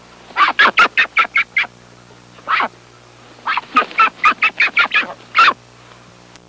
Scared Duck Sound Effect
Download a high-quality scared duck sound effect.
scared-duck.mp3